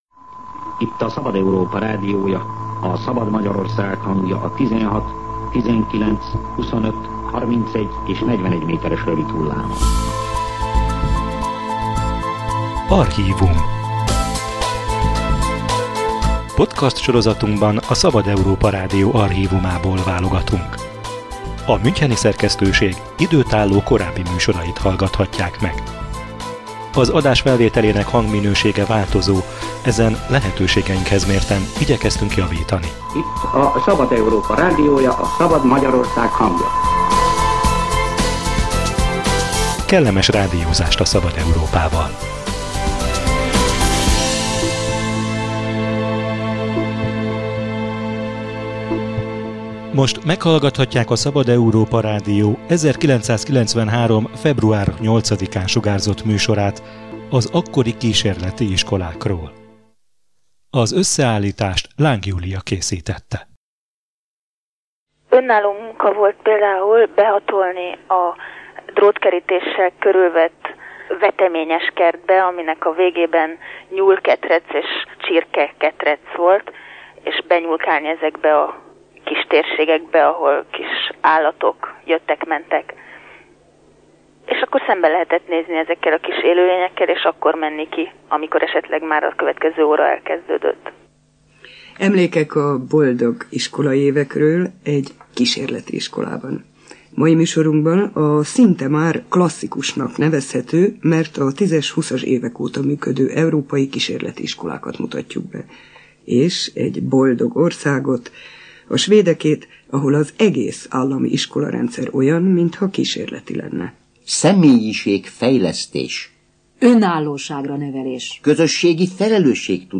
Személyiségfejlesztés, önállóság, közösségi felelősségtudat, életre nevelés - ilyen fogalmakkal hirdették magukat harminc éve a kísérleti iskolák. A Szabad Európa Rádió 1993. február 8-ai adásában a hagyományos iskolarendszer merevségével és a személyesebb nevelés légkörével foglalkoztak.